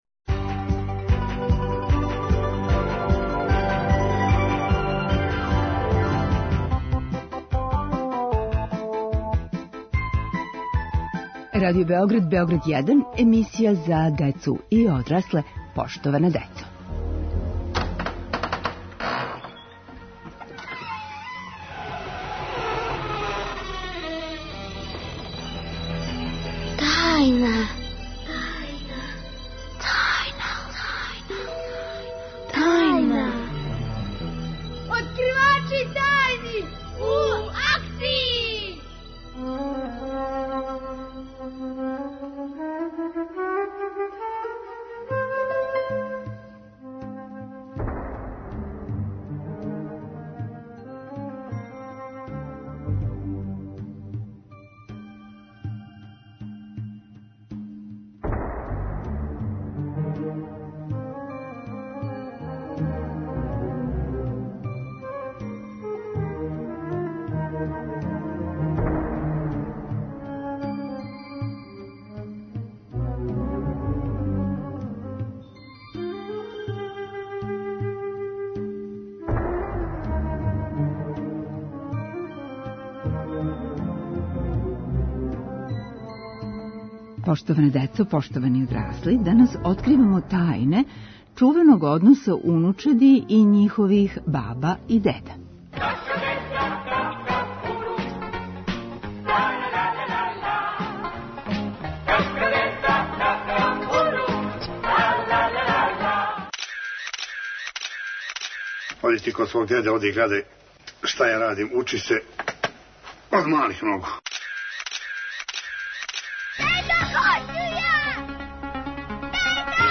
Откривамо тајне одрастања уз бабе и деде. Откривачи су деца, родитељи и родитељи родитеља. У акцији им помажу Заштолог, Зоотајнолог и шумски дописник.